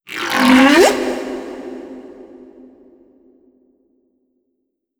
khloCritter_Male05-Verb.wav